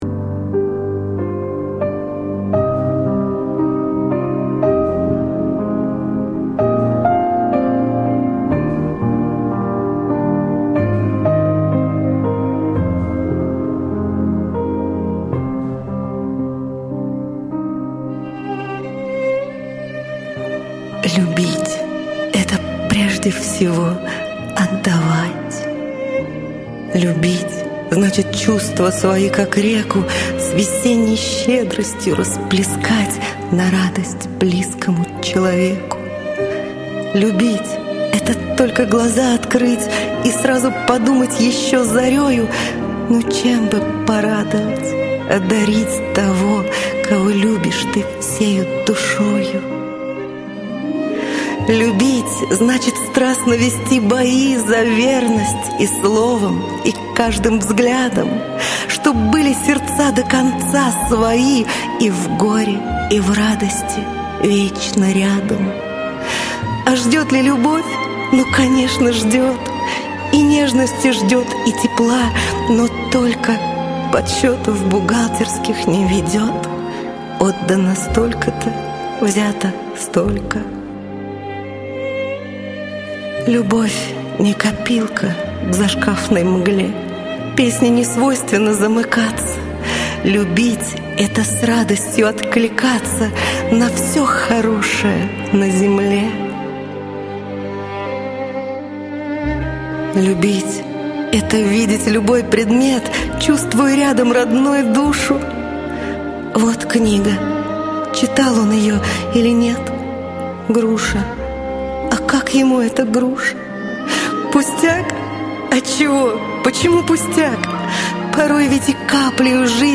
Не менее красивая мелодекламация ...